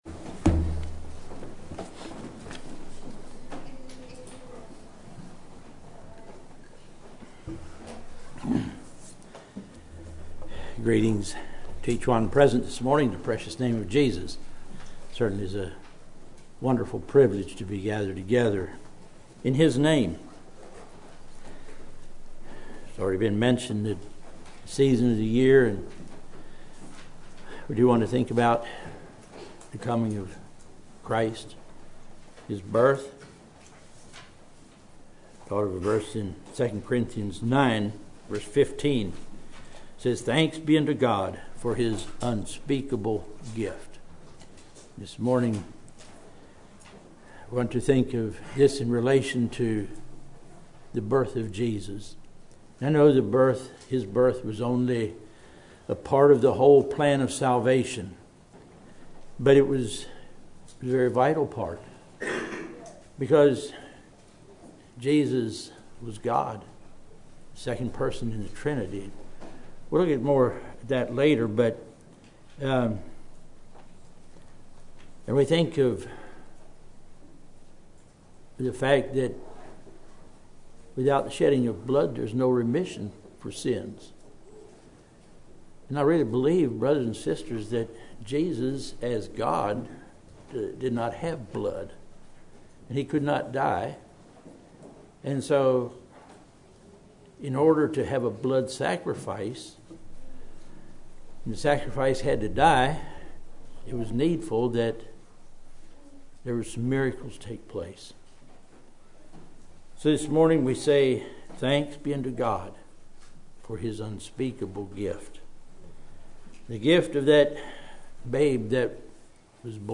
Christmas message; birth of Jesus Christ; fulfilled prophecy; Incarnation; Son of God, Son of Man; Lamb of God; sacrifice for our salvation; glad tidings